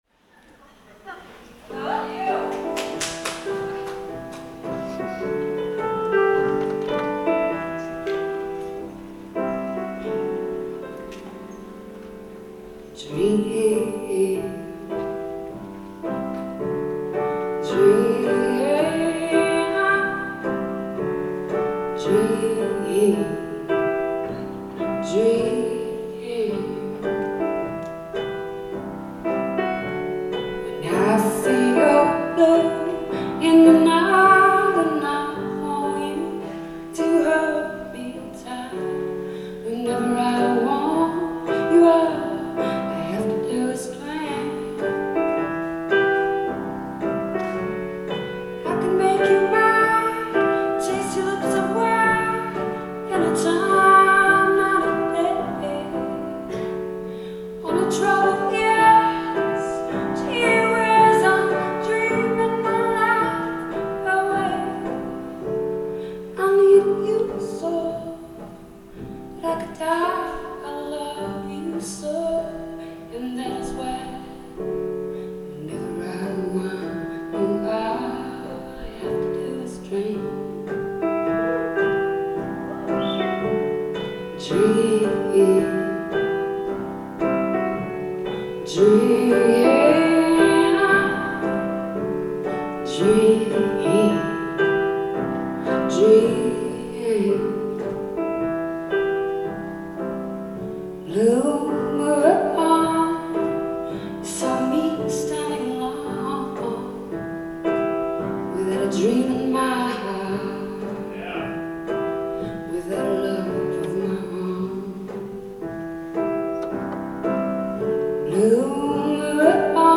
Live at the Berklee Performance Center
Boston, MA